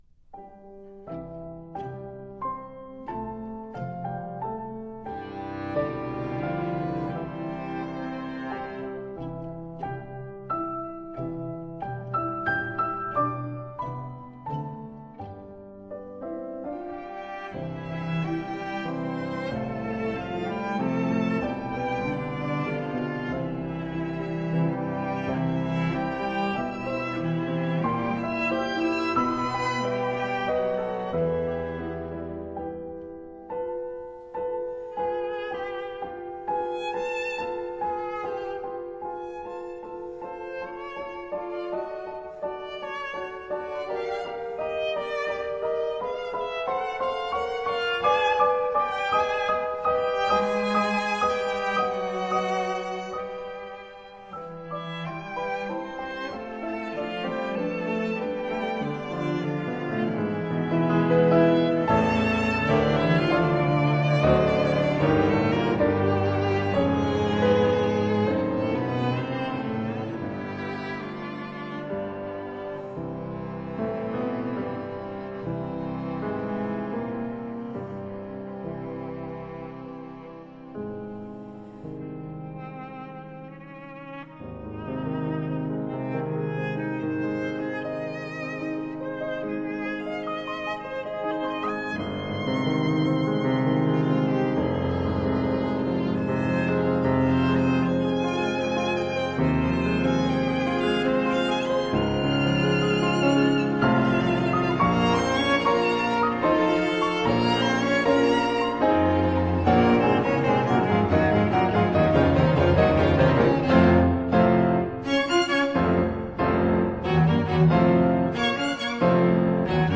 Piano Quartet in e minor
The second movement, Andante, begins in a calm and gentle fashion, only to be interrupted by an intense, almost stormy, middle section.